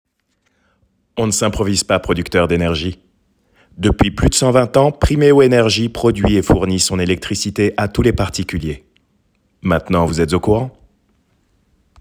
Voix off